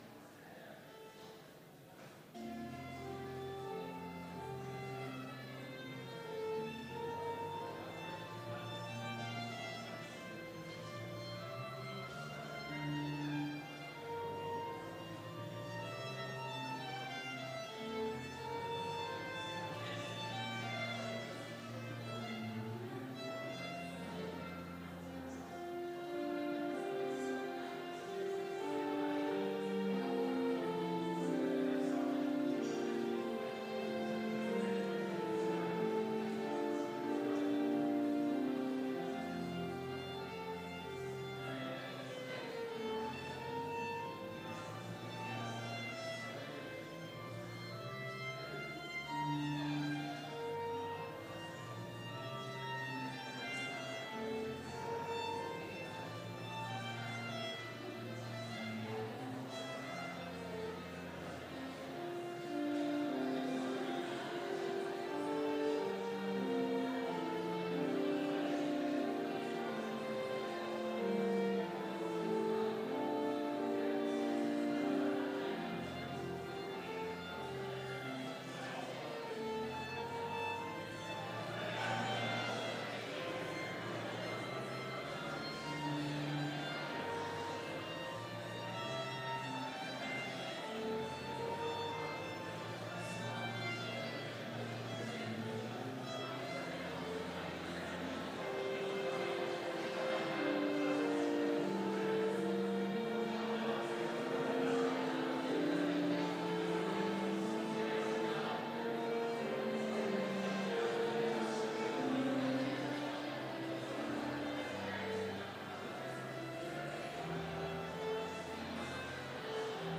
Complete service audio for Chapel - September 19, 2019